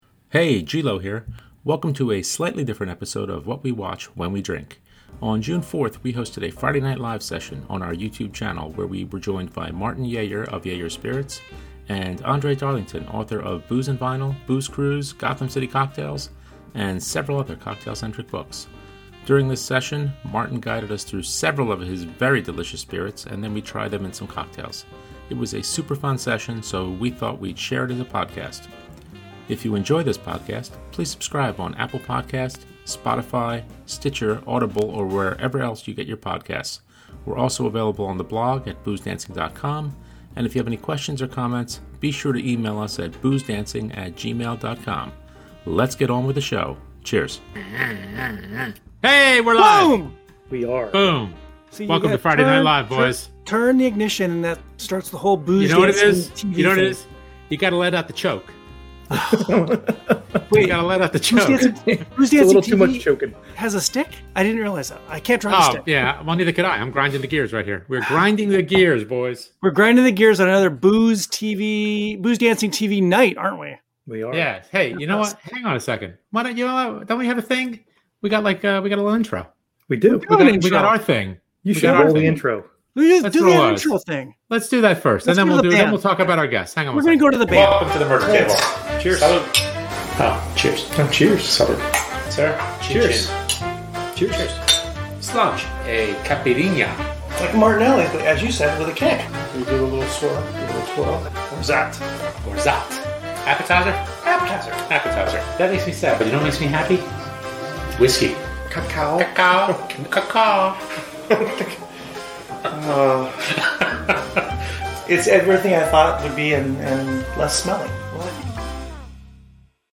It was a super fun session, so we thought we’d share it as a podcast.